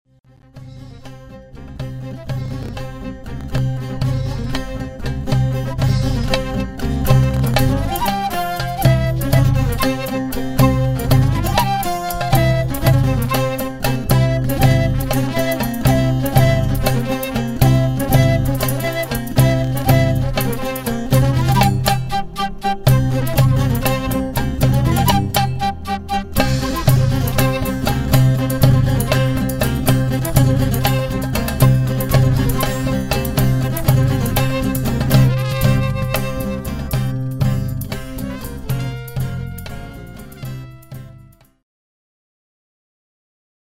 Traditional Serbian folk song